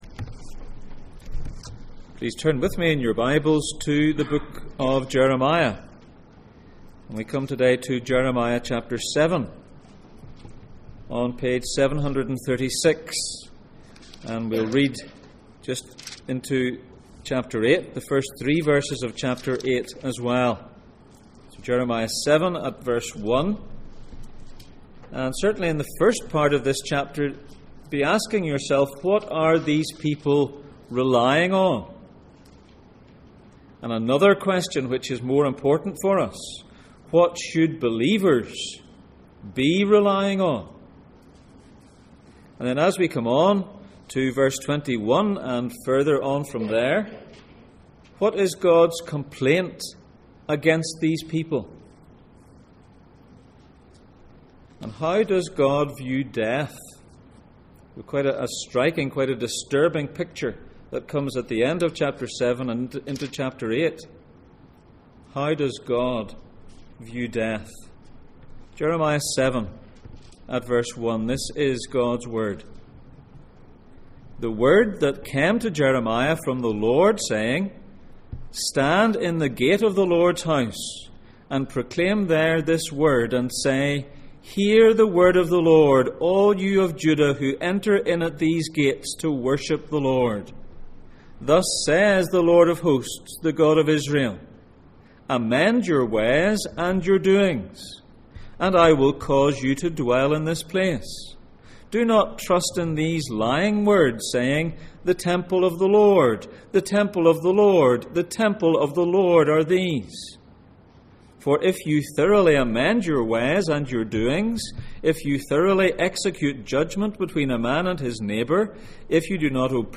John 17:3 Service Type: Sunday Morning %todo_render% « Agin Sin?